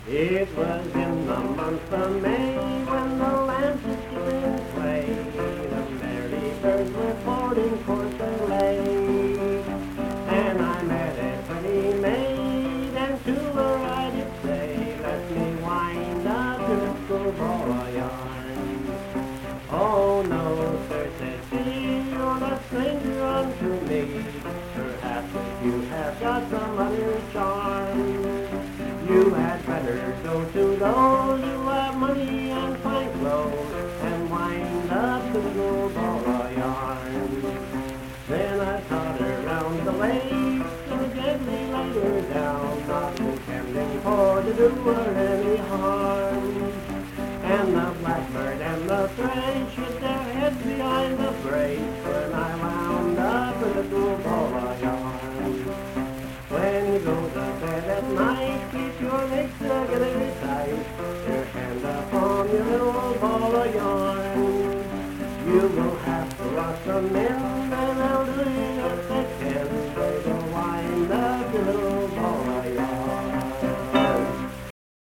Verse-refrain 4(8w/R).
Performed in Hundred, Wetzel County, WV.
Bawdy Songs
Voice (sung), Guitar